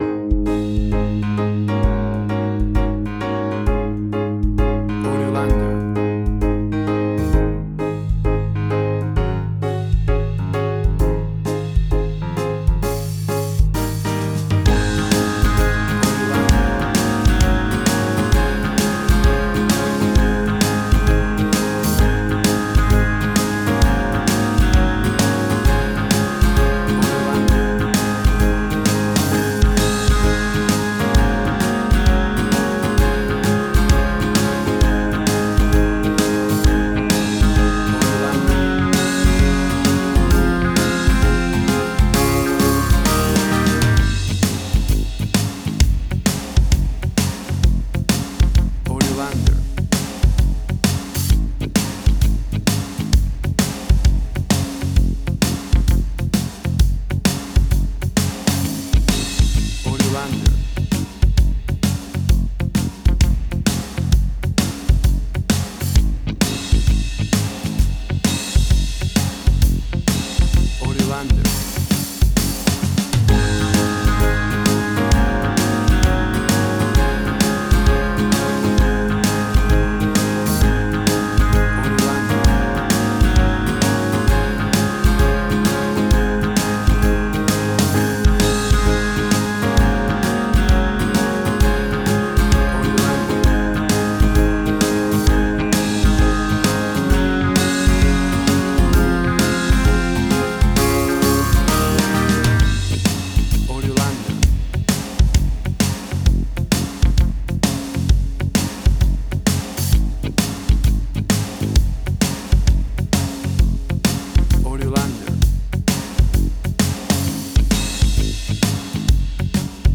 A cool vocal pop song all about new years and new years eve!
Upbeat and Uptempo vocal music with full vocal productio
Tempo (BPM): 132